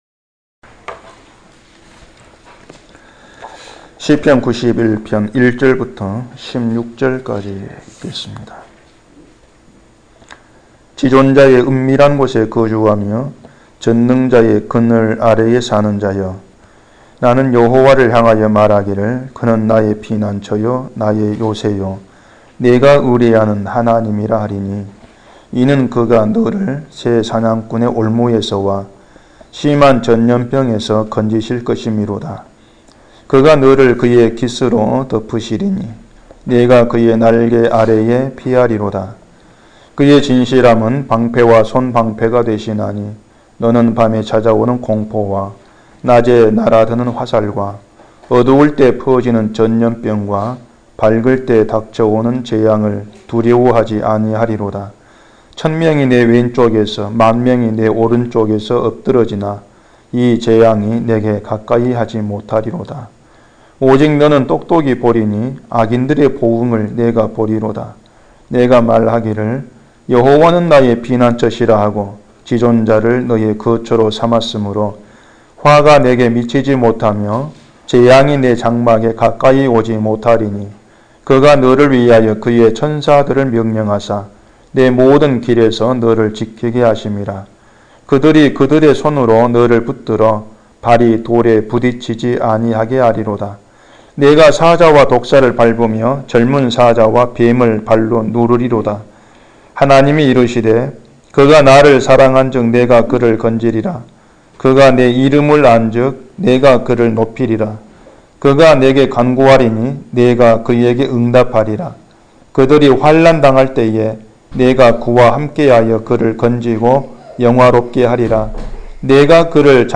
<설교>